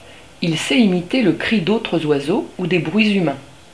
Le cri de l'étourneau.
Il sait imiter le cri d'autres oiseaux ou des bruits humains.